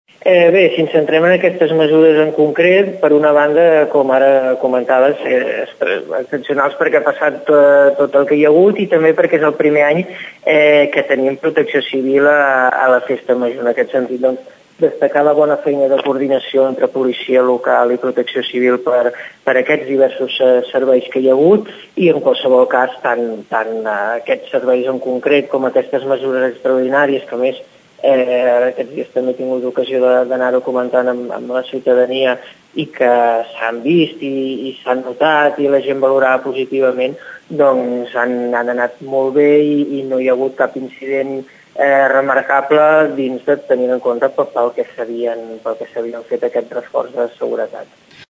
Xavier Martin és regidor de seguretat de l’ajuntament de Tordera.